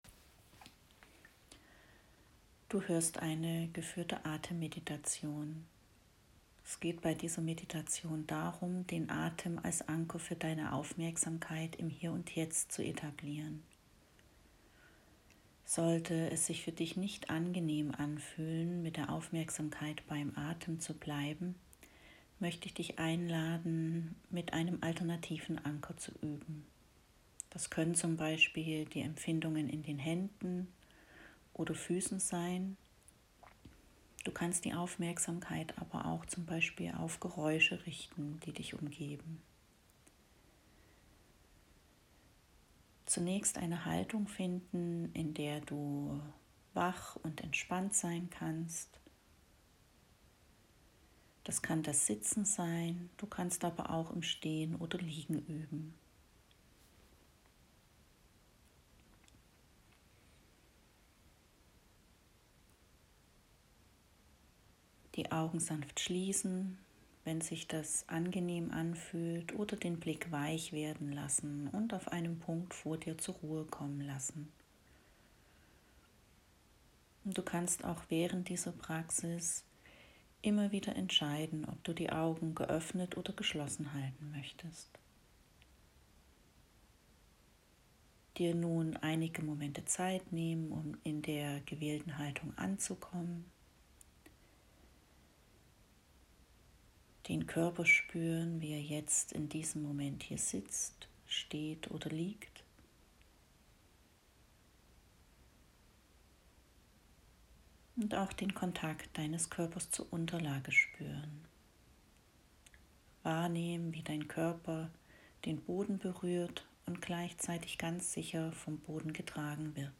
Atemmeditation
Hier findest du eine Anleitung für eine Atemmeditation. Es geht darum, die Aufmerksamkeit bewusst und freundlich auf die Empfindungen des Atems zu richten.